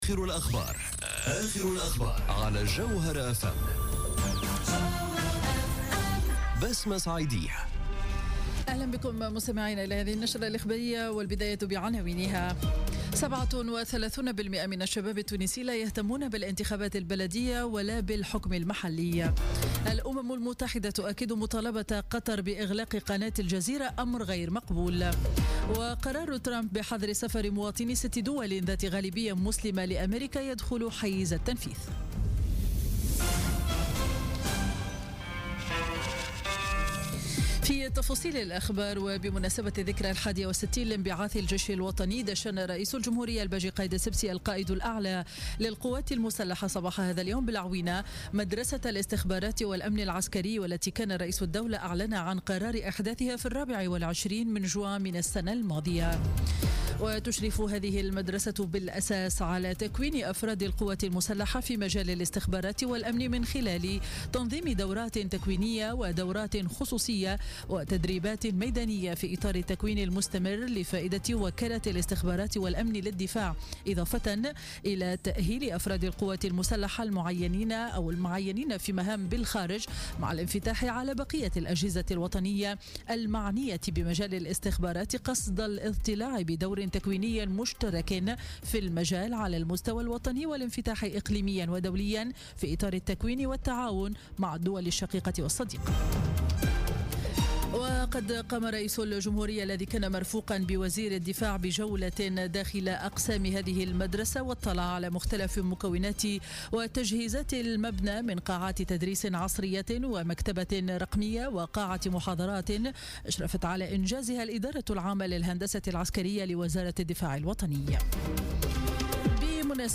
نشرة أخبار منتصف النهار ليوم الجمعة 30 جوان 2017